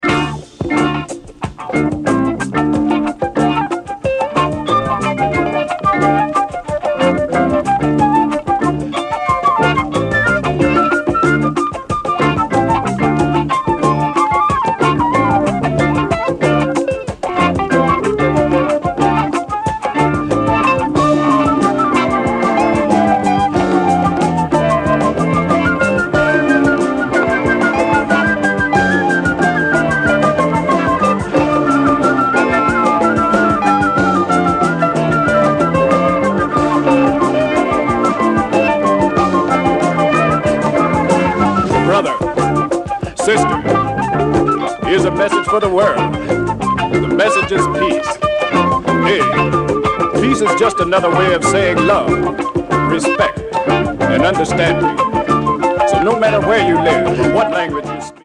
Killer deep spiritual Jazz compilation.